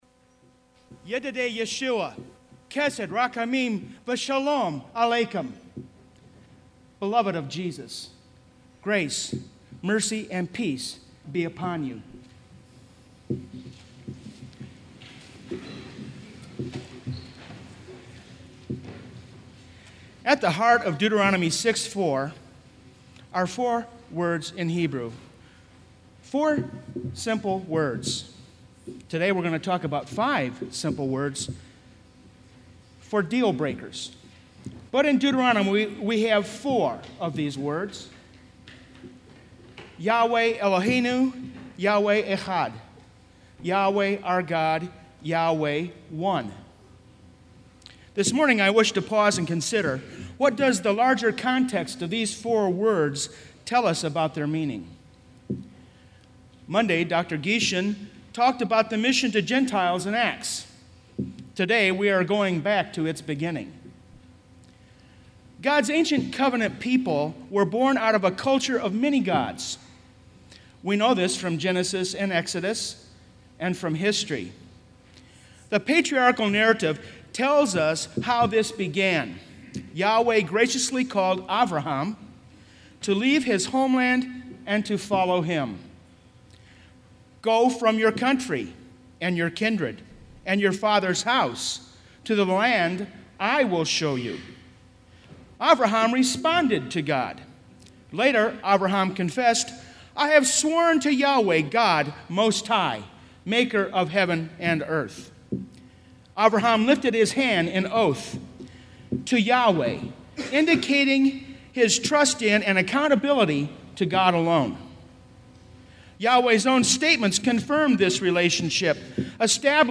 Kramer Chapel Sermon - June 14, 2006